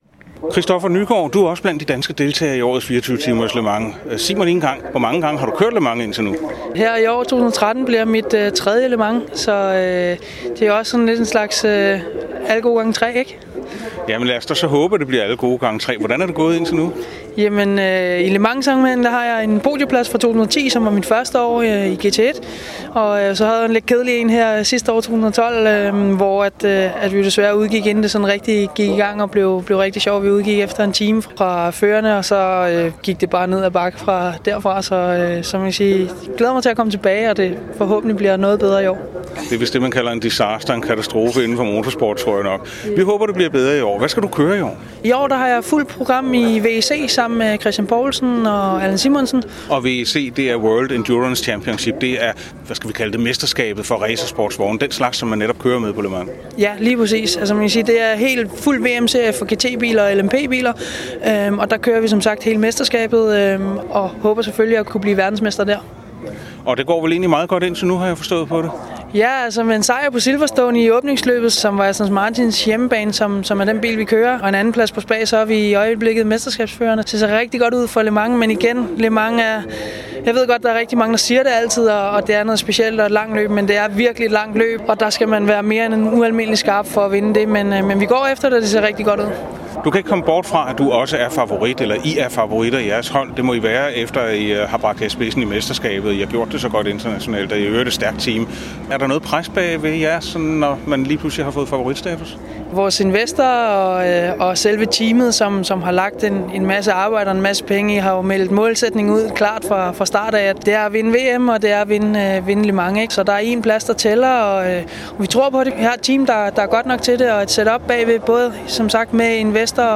Interview i Motorradioen